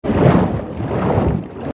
AV_swimming.ogg